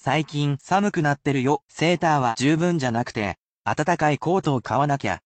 They are at a regular pace which may be difficult.
[emphatic casual speech]